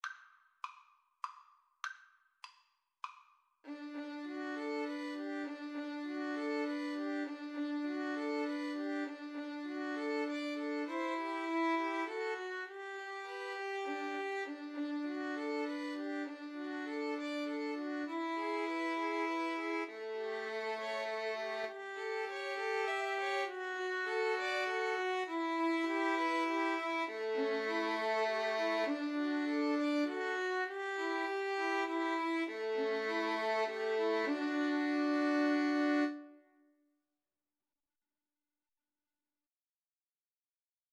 Play (or use space bar on your keyboard) Pause Music Playalong - Player 1 Accompaniment Playalong - Player 3 Accompaniment reset tempo print settings full screen
D major (Sounding Pitch) (View more D major Music for Violin Trio )
3/4 (View more 3/4 Music)
Moderato